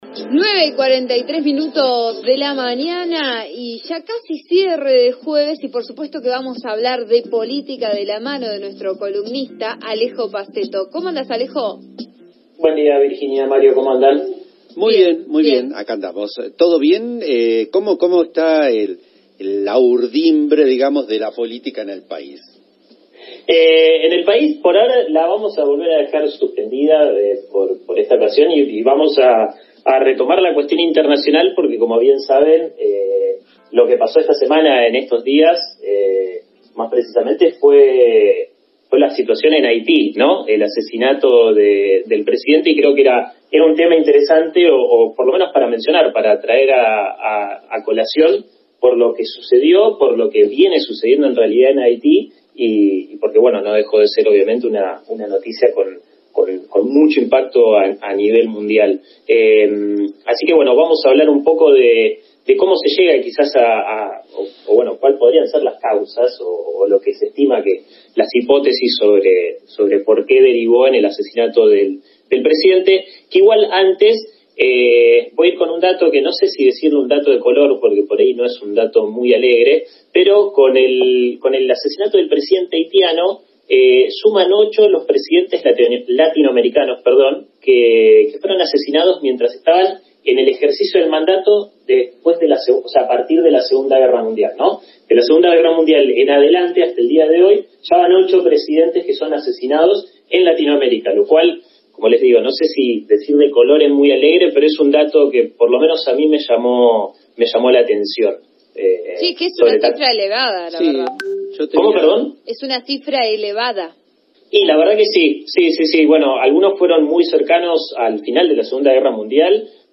en su columna de Vos A Diario por RN RADIO 89.3